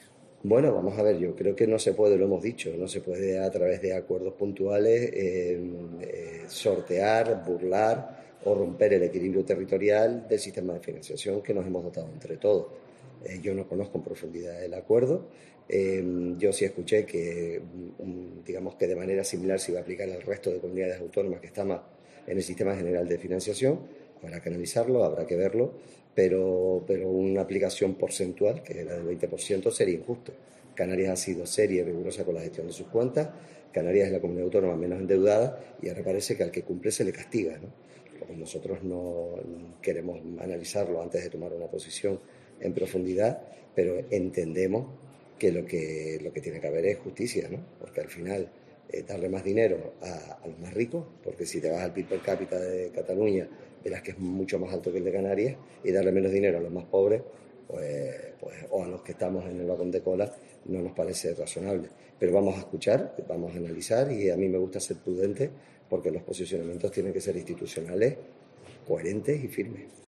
Así lo ha dicho este viernes en declaraciones a los medios de comunicación el también líder de Coalición Canaria al ser preguntado por el acuerdo entre PSOE y ERC que incluye una condonación de 15.000 millones de deuda de Cataluña.